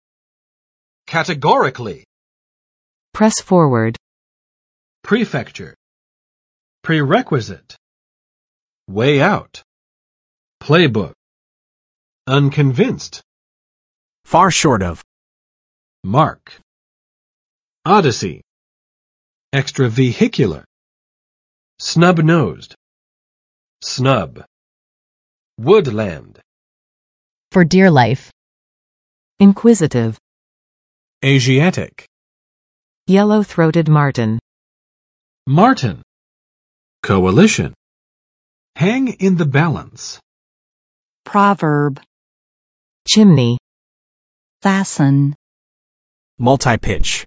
[͵kætəˋgɔrɪklɪ] adv. 明确地；直截了当地
categorically.mp3